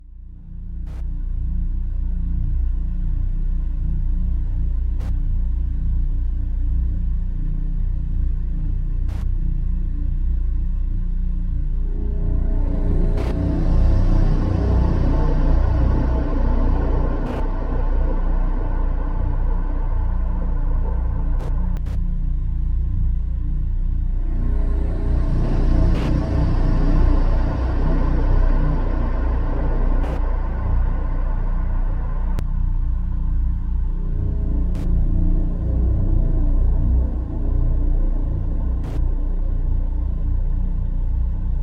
Нагоняющие страх звуки для монтажа видео и просто испугаться слушать онлайн и скачать бесплатно.